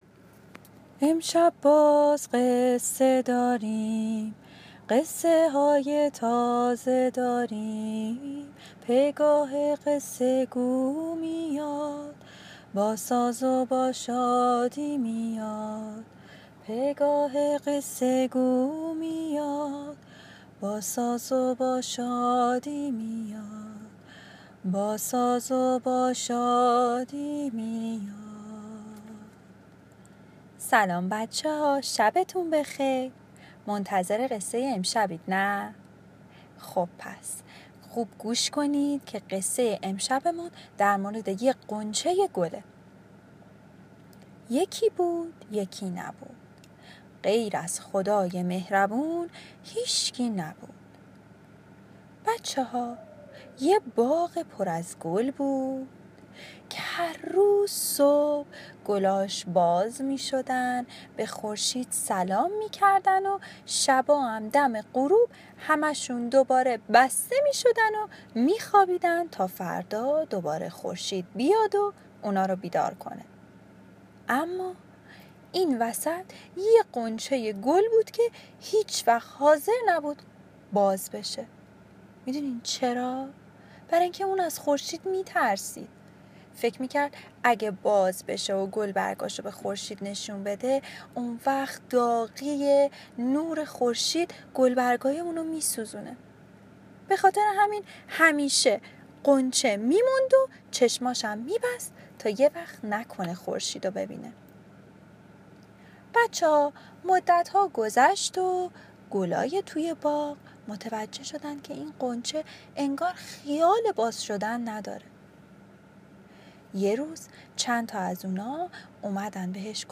قصه صوتی کودکان دیدگاه شما 15,516 بازدید